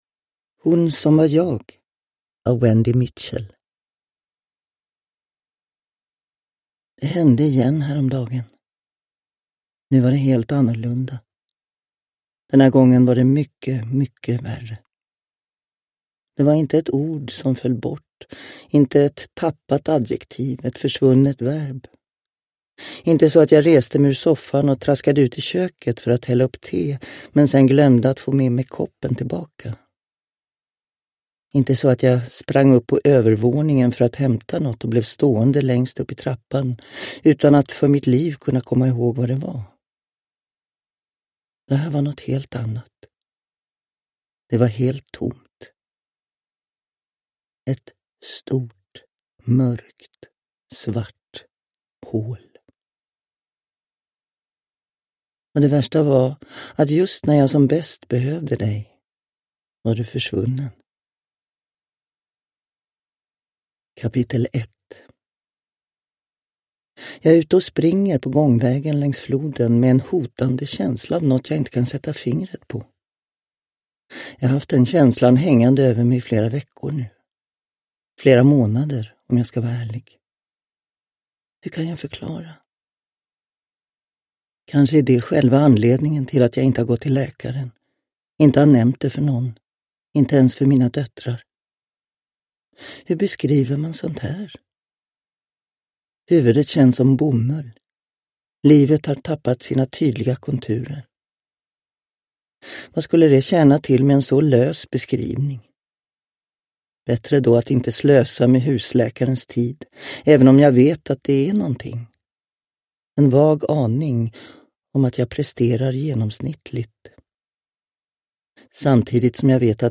Uppläsare: Gunnel Fred